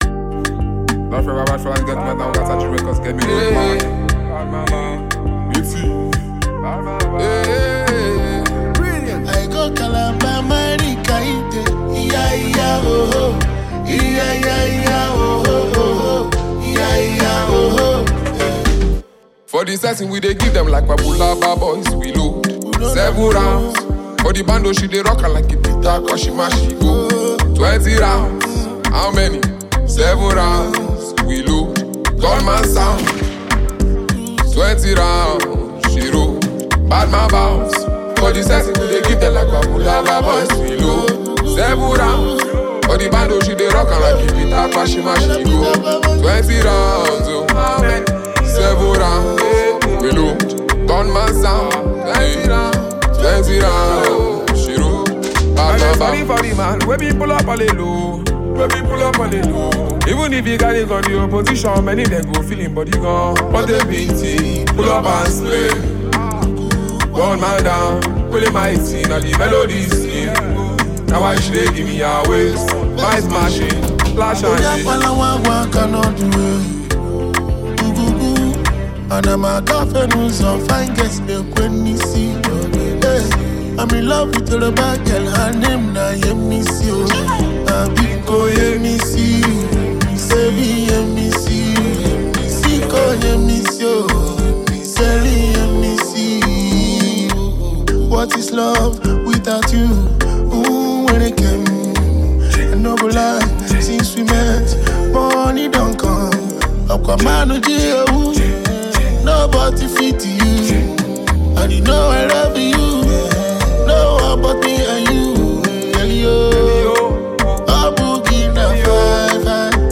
Phenomenon talented Nigerian rap artist and performer